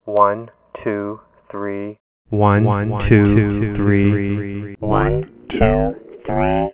The inputs to a Concatenation are heard serially, one after another.